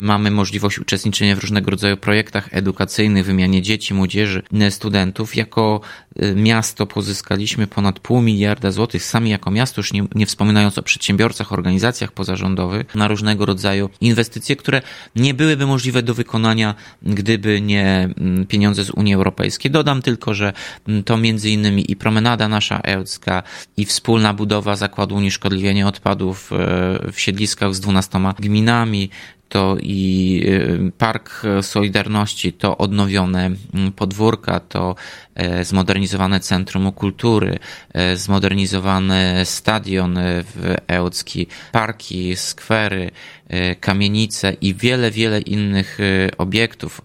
– Wstąpienie do Unii to wymierne korzyści – dodaje włodarz Ełku.